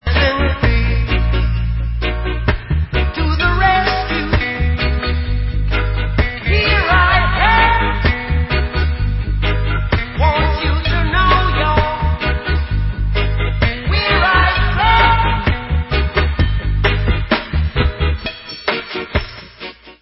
sledovat novinky v oddělení World/Reggae